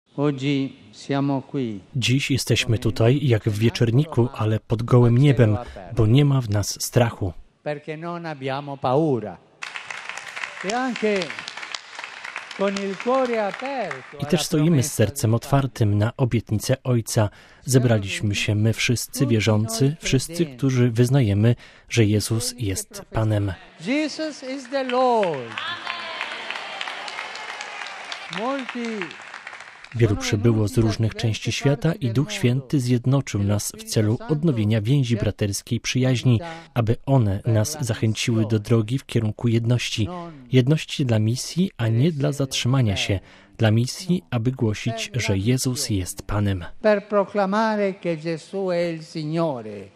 Franciszek obchodził Wigilię Zesłania Ducha Świętego na rzymskim Circo Massimo wraz z członkami odnowy charyzmatycznej z całego świata.